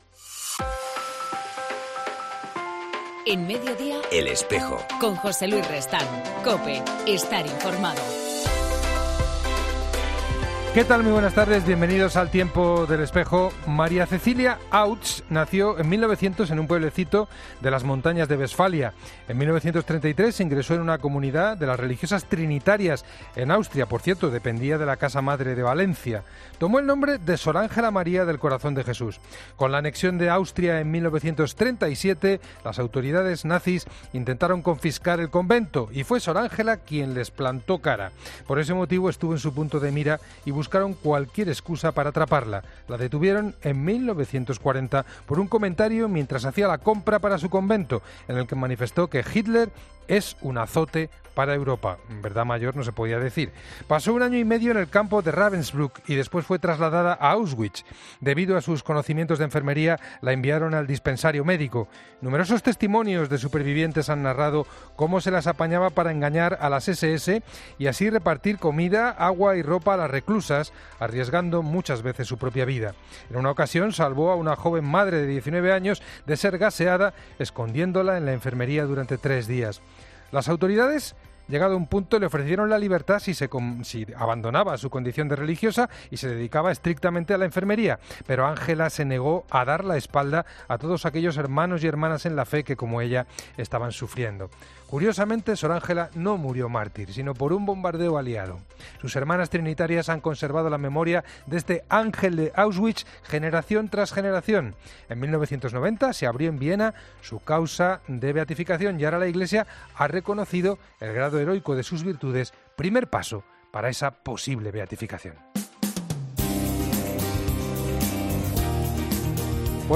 hoy entrevistamos